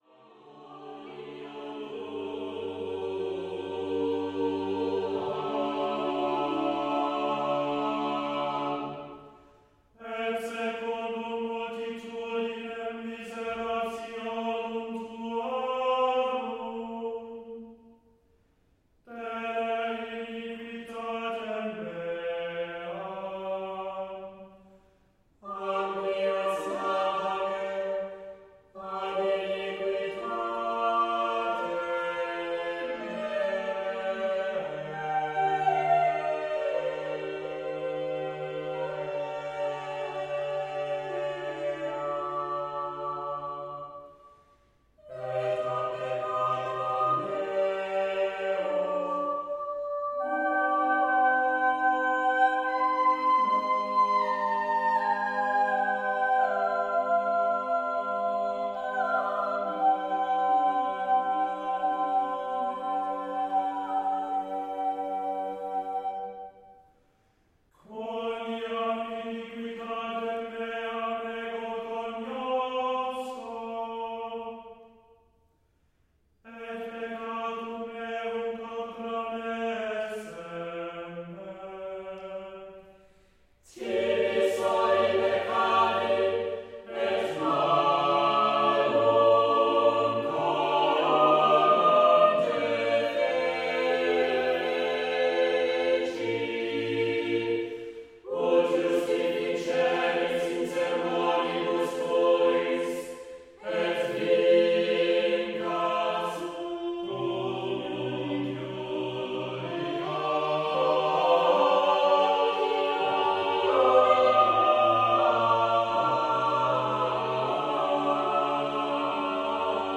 Discover Music of the Baroque Era (EN) audiokniha
Ukázka z knihy
Bach, Vivaldi and Handel are the three great composers of the Baroque era, though there are many other well-known figures, including Albinoni, Pachelbel and Scarlatti. This engaging introduction, interspersed liberally music, shows how the music of these composers graced the courts and churches of Europe from 1600-1750.